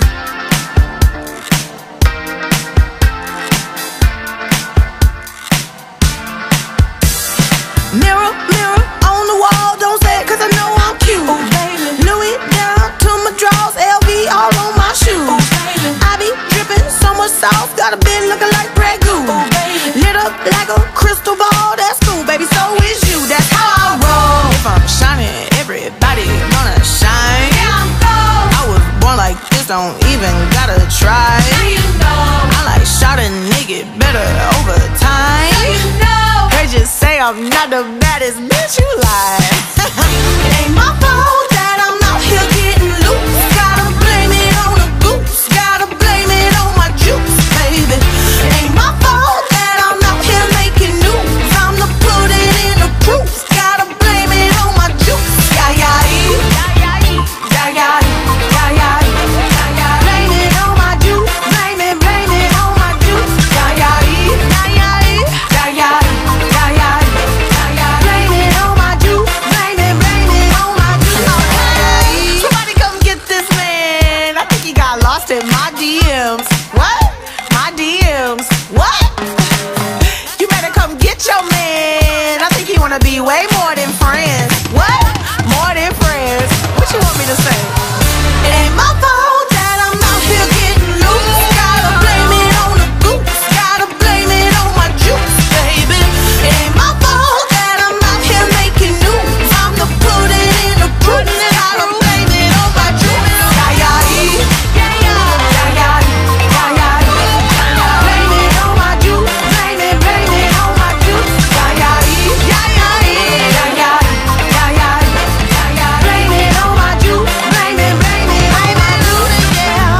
BPM120
Comments[MAINSTREAM POP]